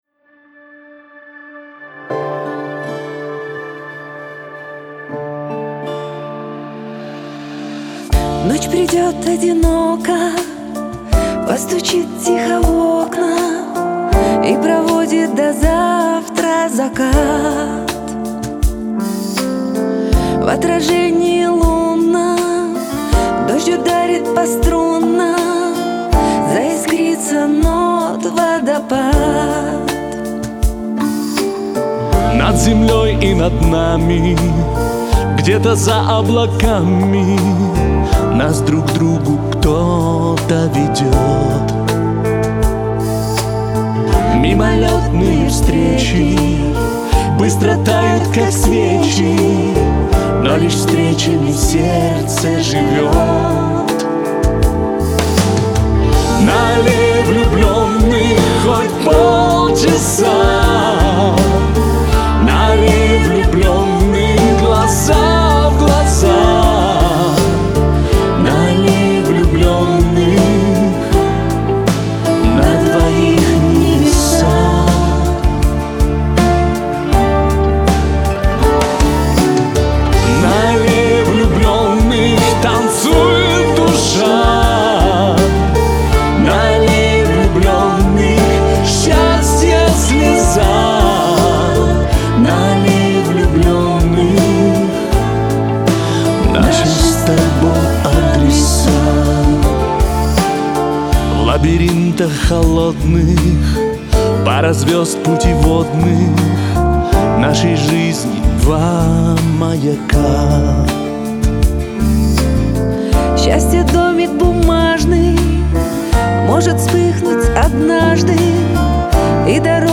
дуэт
Лирика , pop